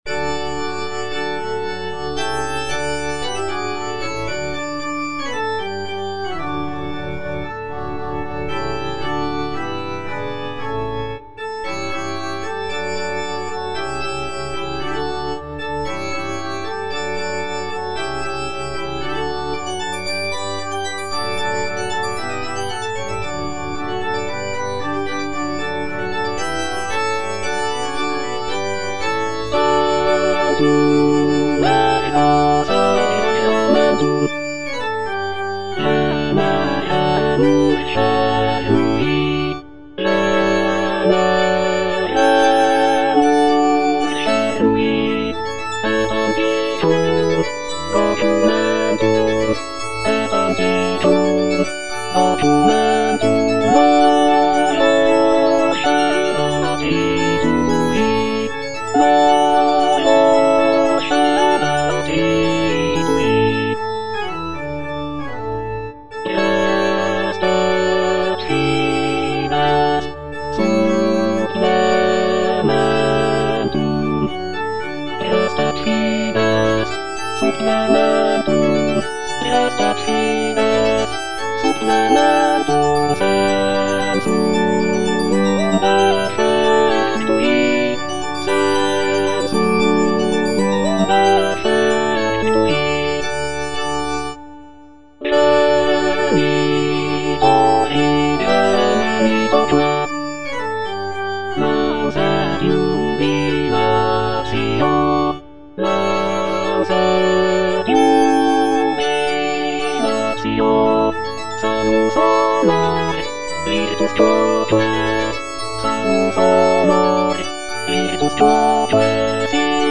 W.A. MOZART - TANTUM ERGO KV197 (All voices) Ads stop: auto-stop
"Tantum ergo KV197" is a sacred choral work composed by Wolfgang Amadeus Mozart in 1774. It is based on the Latin hymn "Tantum ergo sacramentum" and was intended for liturgical use during the vespers service.
With its serene and contemplative character, "Tantum ergo KV197" remains a testament to Mozart's exceptional talent in composing religious music.